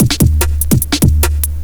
JUNGLE6-R.wav